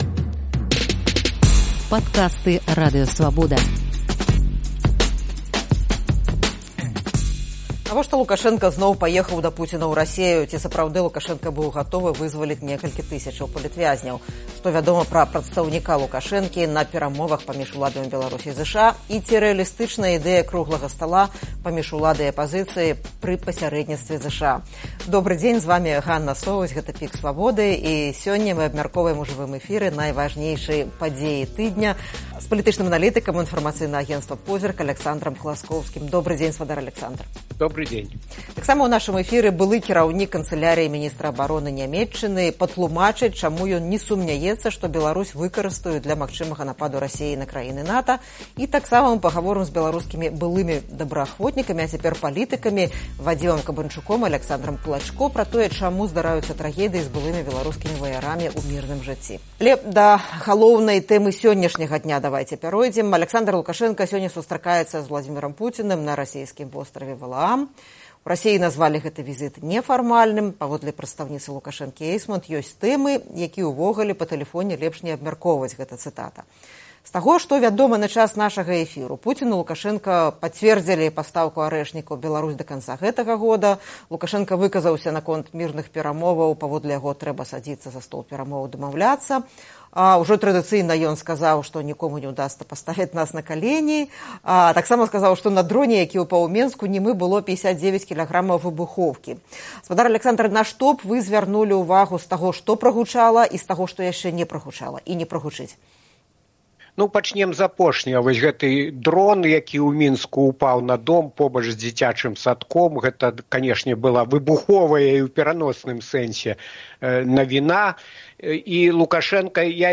Ці рэалістычная ідэя круглага стала паміж уладай і апазыцыяй пры пасярэдніцтве ЗША? Абмяркоўваем найважнейшыя падзеі тыдня з палітычным аналітыкам